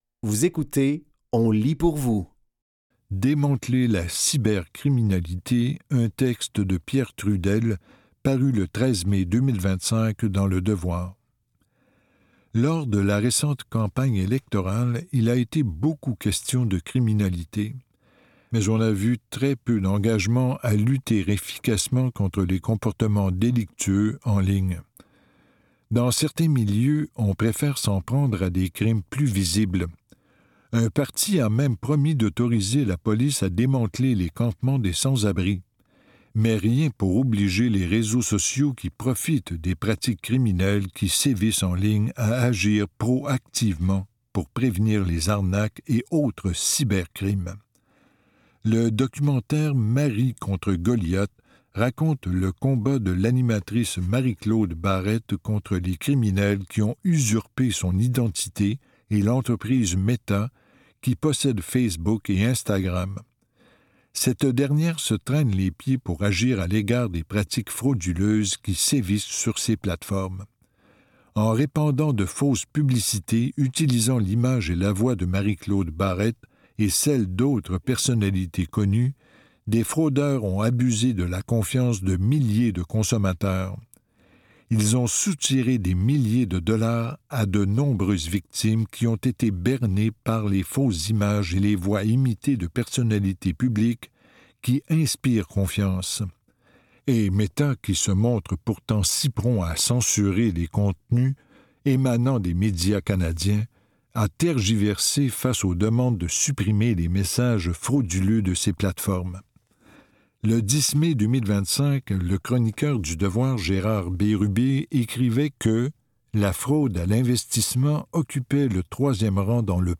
Dans cet épisode de On lit pour vous, nous vous offrons une sélection de textes tirés des médias suivants : Le Devoir, Fugues et La Presse. Au programme: Démanteler la cybercriminalité, un texte de Pierre Trudel, paru le 13 mai 2025 dans Le Devoir.